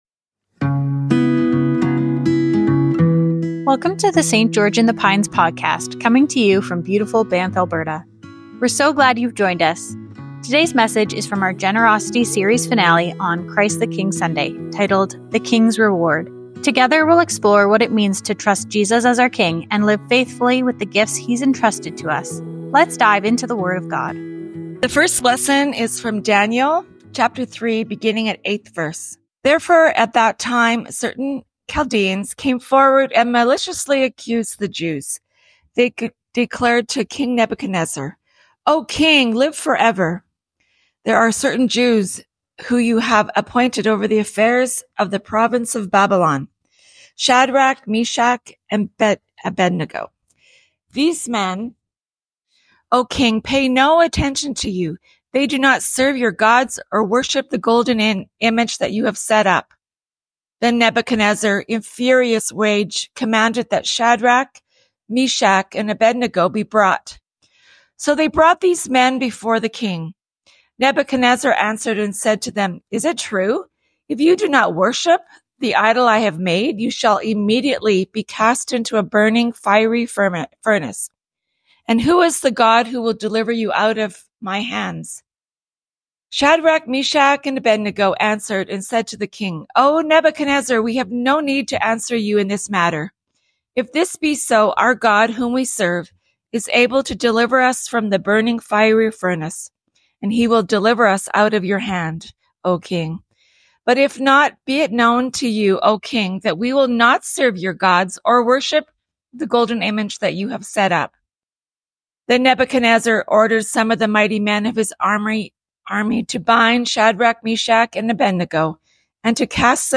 Sermons | St. George in the Pines Anglican Church